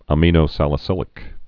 (ə-mēnō-sălĭ-sĭlĭk, ămə-nō-)